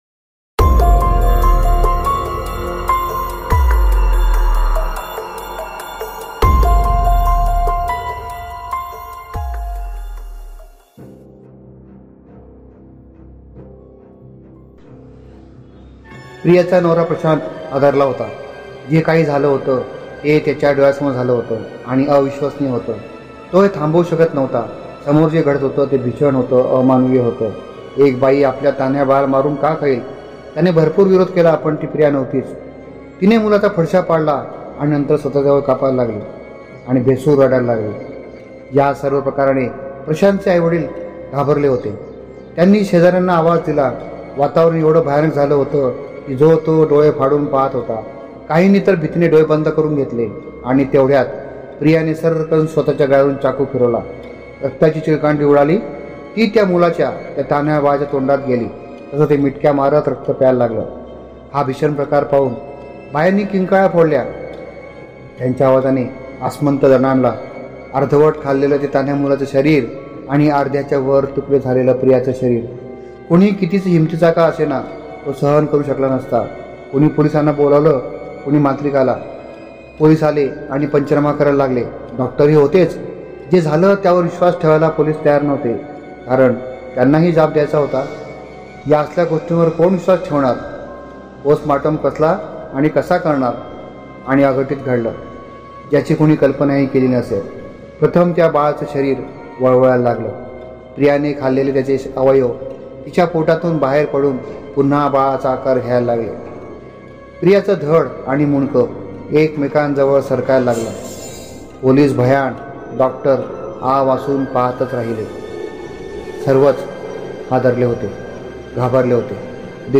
सवाष्ण | मराठी भयकथा | Savashna | marathi Horror story | Audio book this is just for trial